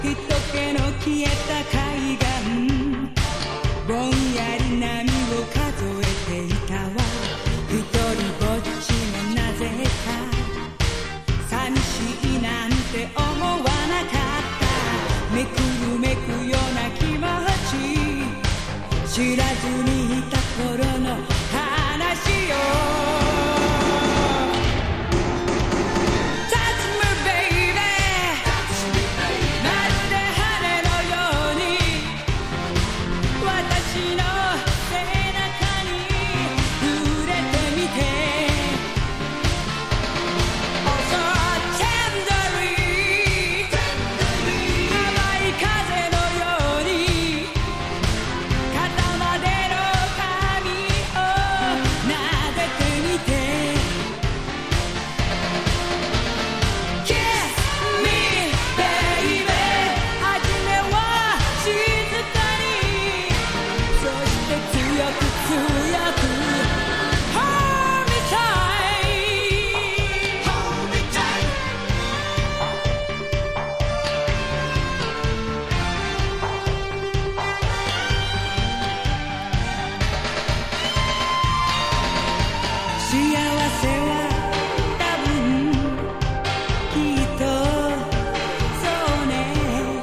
• R&B
# CITY POP / AOR